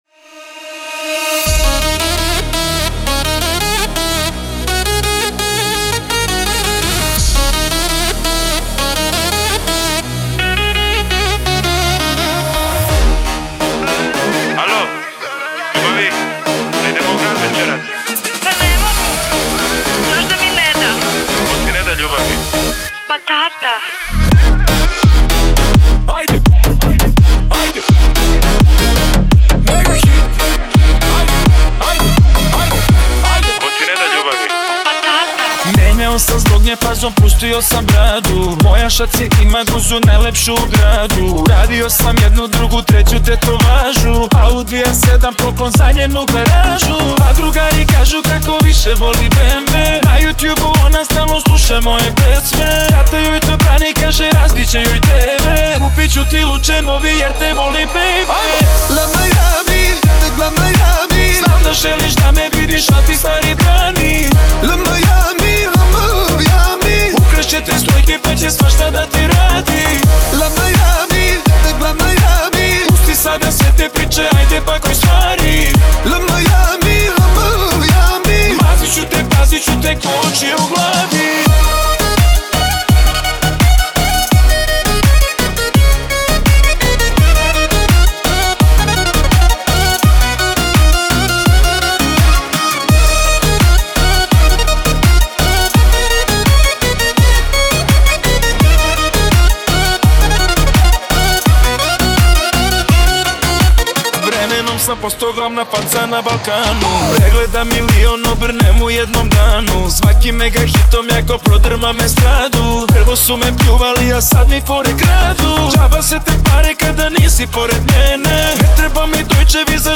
REMIXES - MP3 FILES